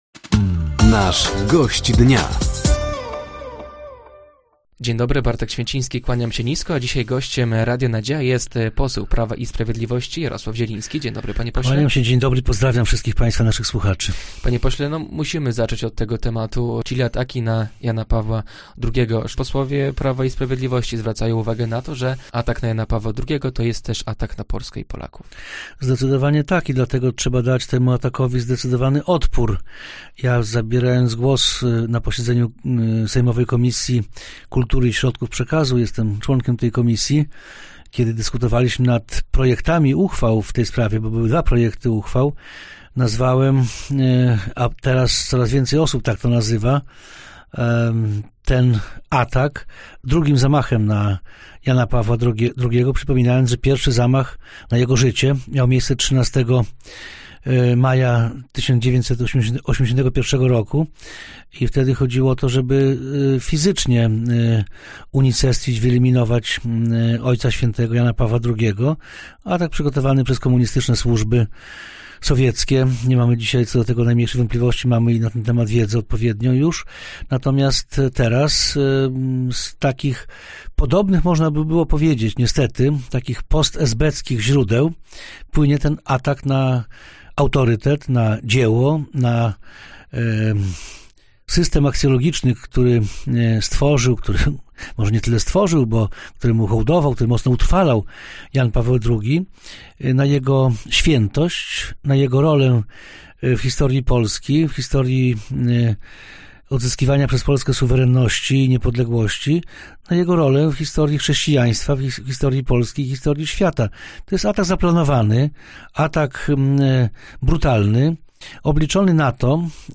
Gościem Dnia Radia Nadzieja był dzisiaj poseł Prawa i Sprawiedliwości, Jarosław Zieliński. Tematem rozmowy były między innymi lokale wyborcze i papież święty Jan Paweł II.